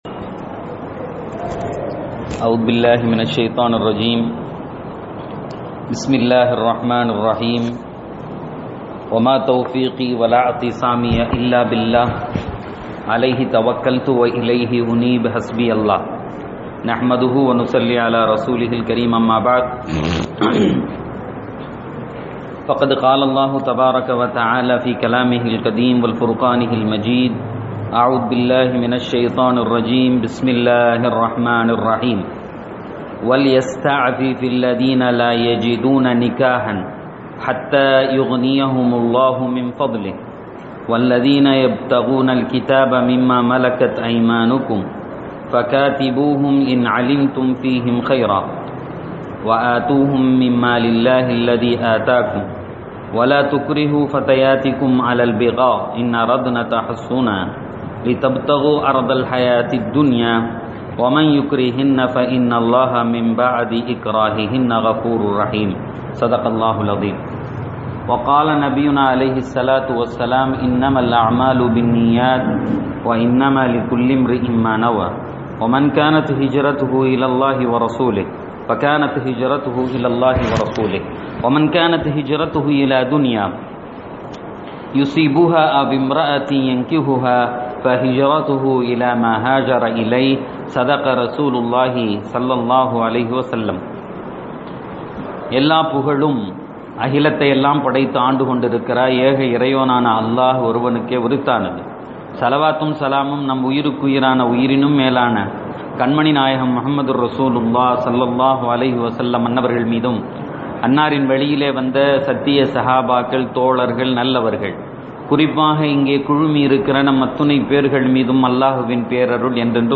அந் நூர் விளக்கவுரை: ஆயா: 32-33 (29-Sep-2019) 24:32.